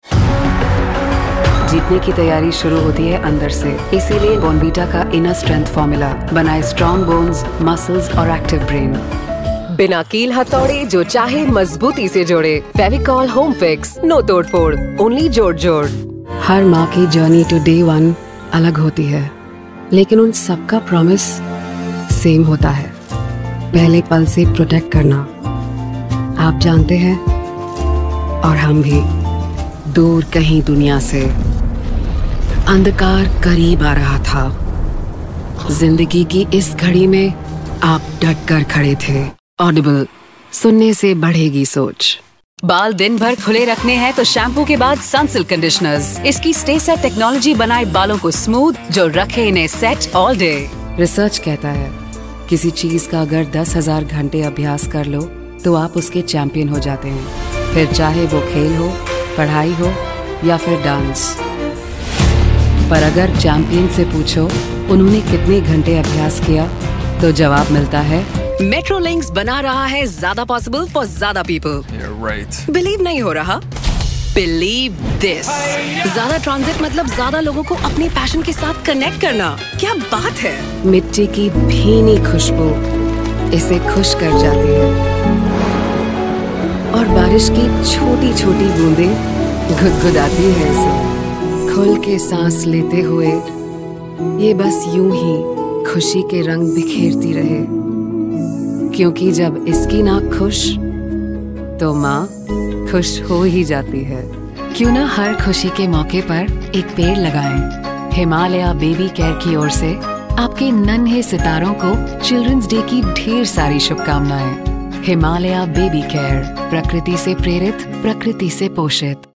Hundreds of voice talent for radio and TV commercials.